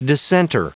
Prononciation du mot dissenter en anglais (fichier audio)
Prononciation du mot : dissenter